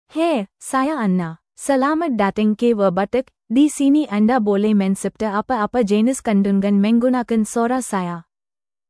FemaleMalayalam (India)
AnnaFemale Malayalam AI voice
Voice sample
Female
Anna delivers clear pronunciation with authentic India Malayalam intonation, making your content sound professionally produced.